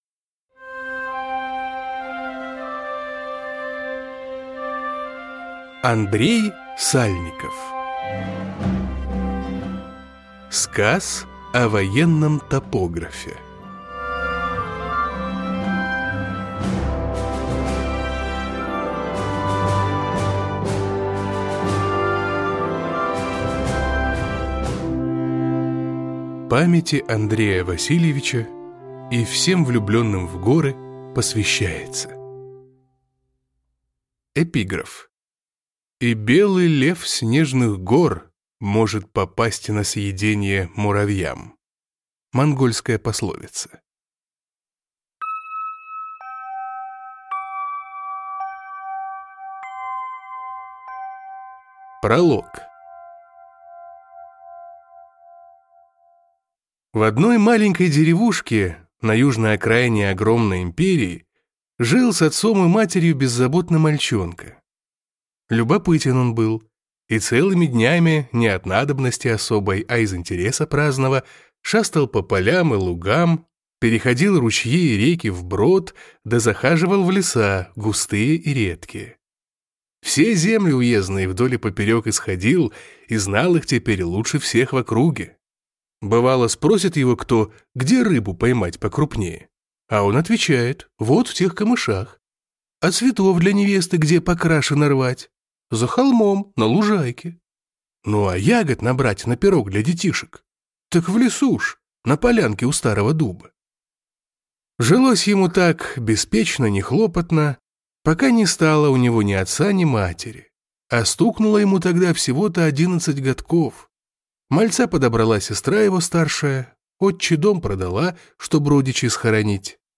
Аудиокнига Сказ о военном топографе | Библиотека аудиокниг
Прослушать и бесплатно скачать фрагмент аудиокниги